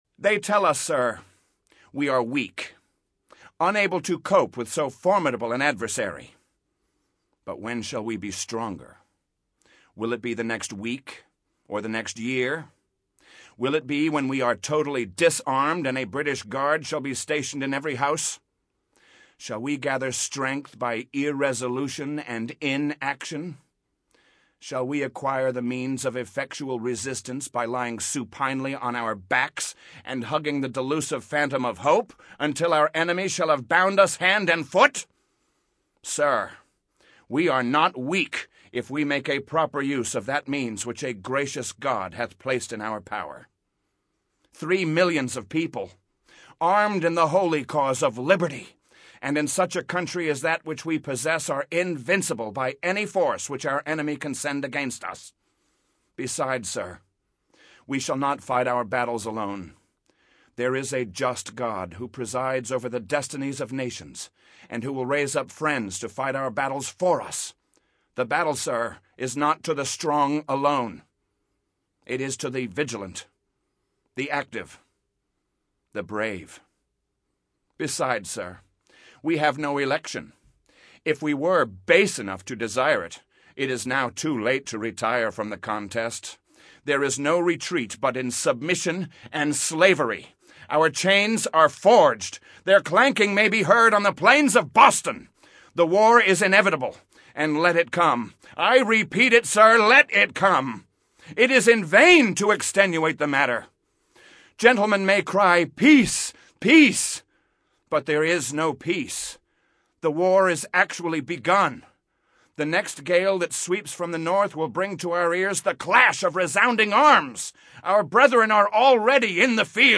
He re-creates Patrick Henry's powerful speech.
Patrick Henry - Speech - Give Me Liberty_short.mp3